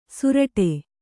♪ suraṭe